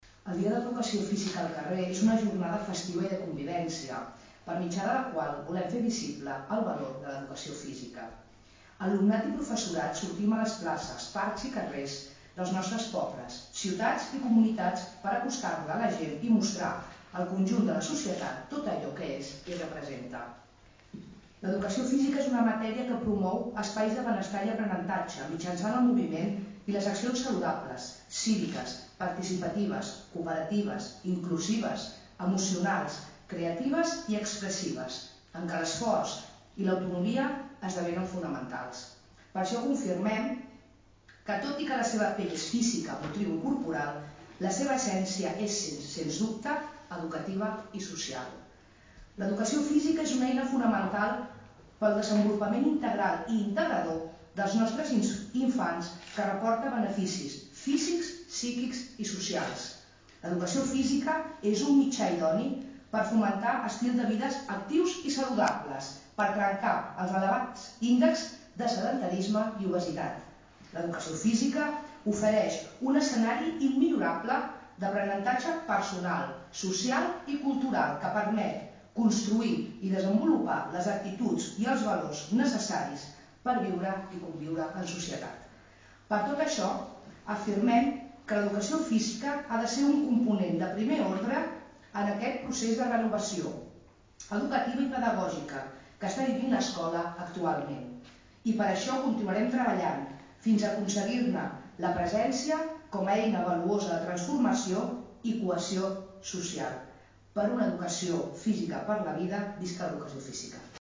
Aquest dimecres, s’ha dut a terme a la Sala de Plens de l’Ajuntament, la presentació institucional d’aquesta jornada esportiva, que se celebrarà el pròxim 26 d’abril.